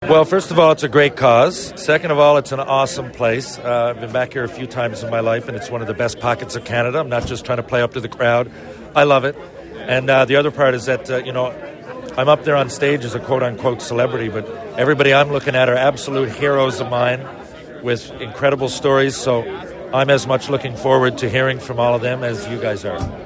myFM had the chance to speak with some of the head table  guests ahead of the sit-down portion of the evening, who shared why they wanted to get involved in such a special event.